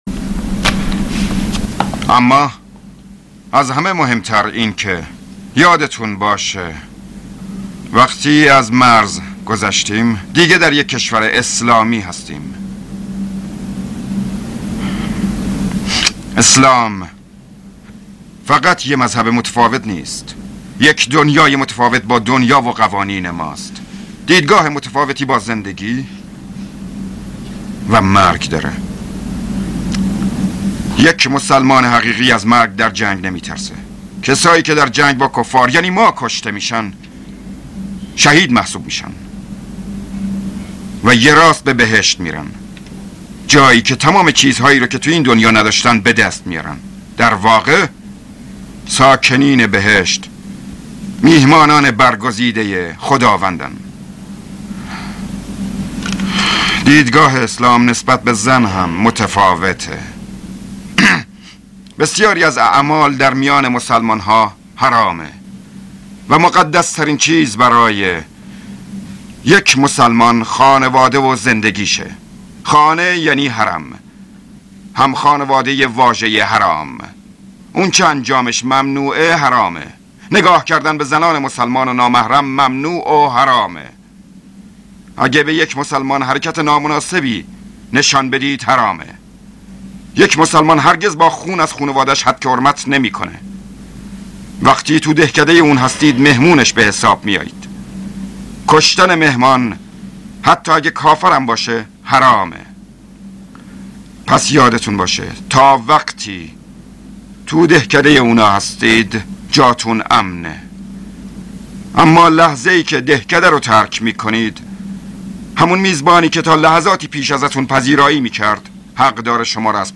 گرفته شده از یک فیلم .